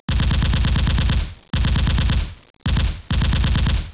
machinegun.wav